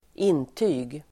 Uttal: [²'in:ty:g]